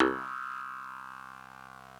genesis_bass_024.wav